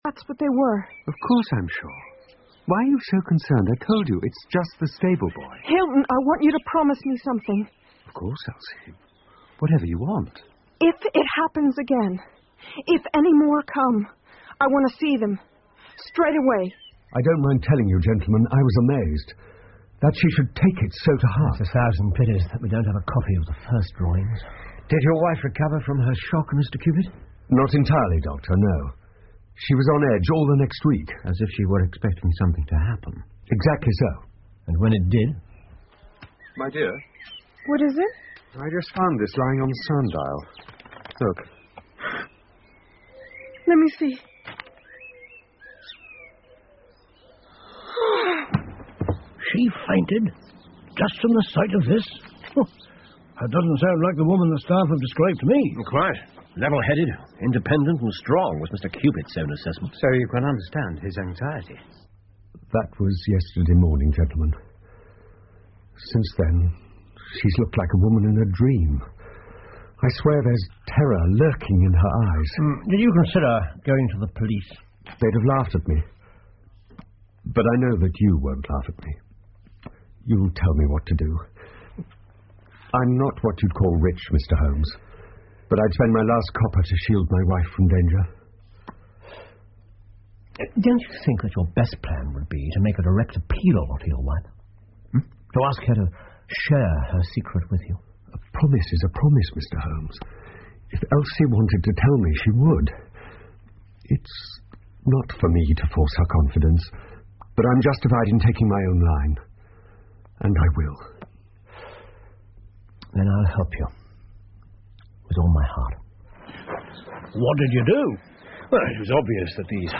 福尔摩斯广播剧 The Dancing Men 5 听力文件下载—在线英语听力室